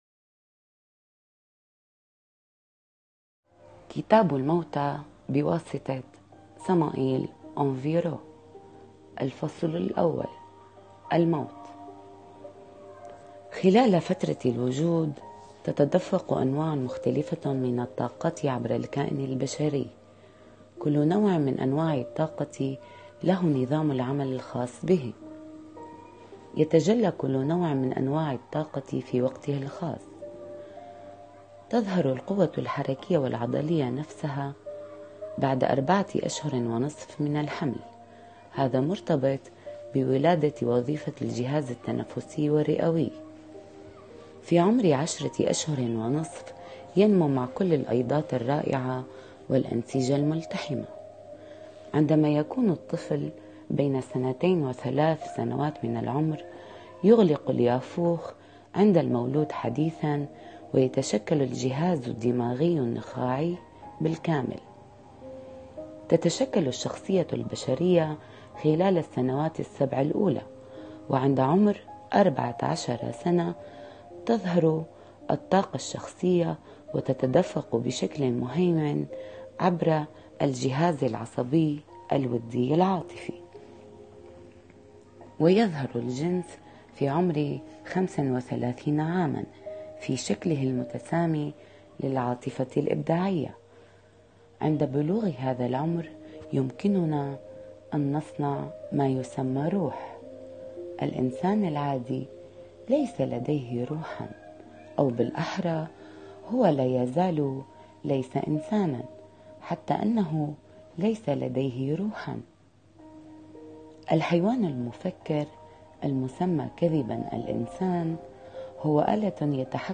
كتب مسموعة